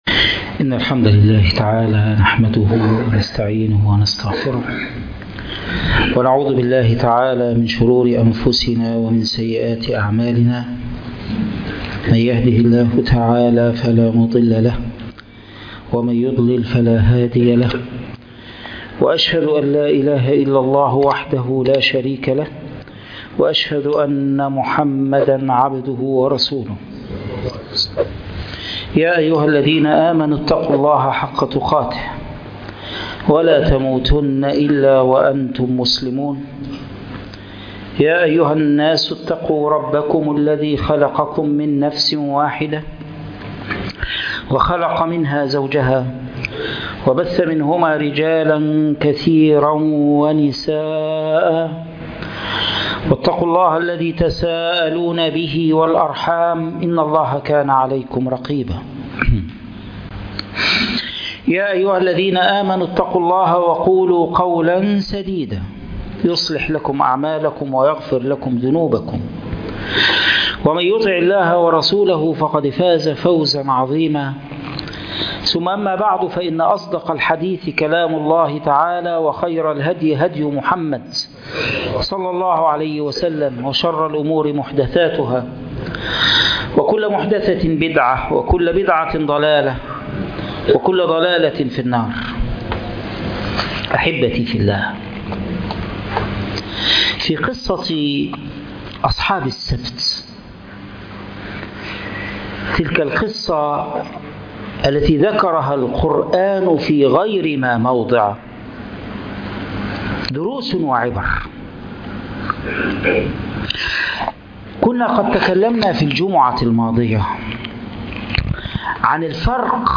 أصحاب السبت دروس وعبر 2 - خطبة الجمعة